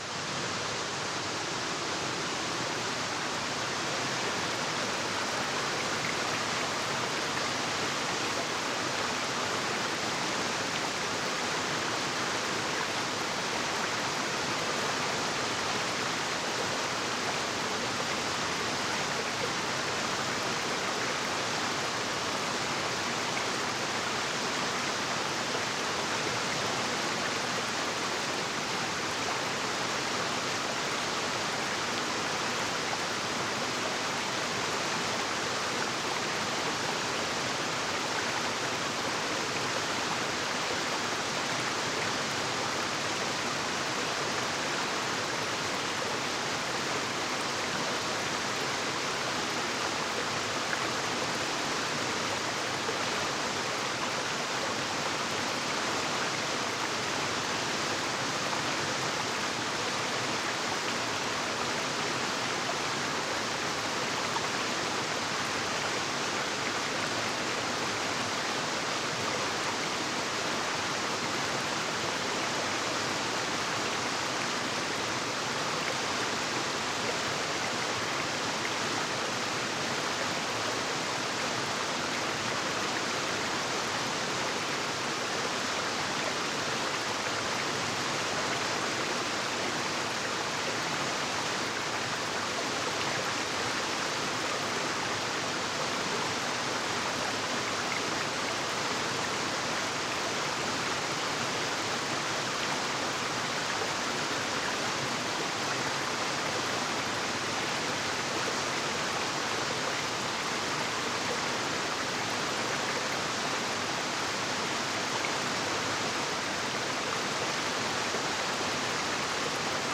GEIST-ÖFFNUNG: Waldgeflüster-Öffnung mit Natur-Stimmen